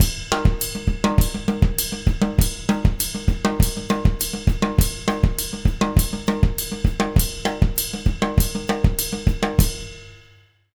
100SONGO01-L.wav